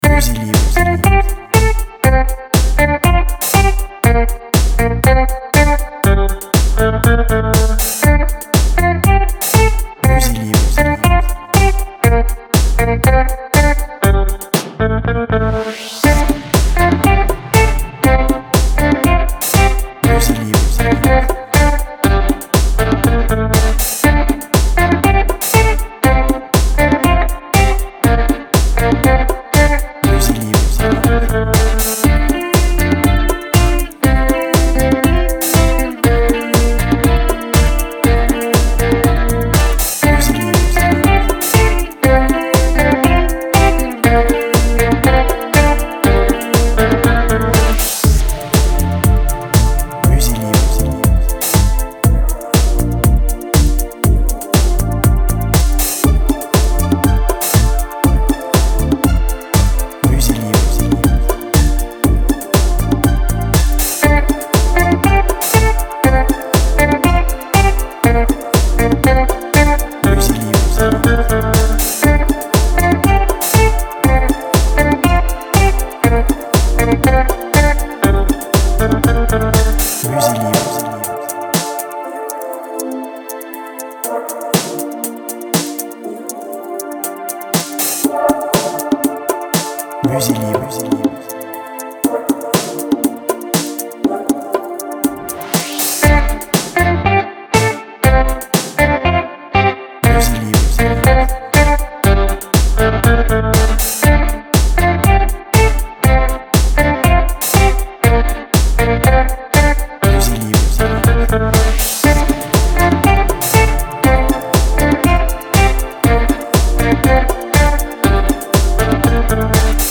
Chanson electro folk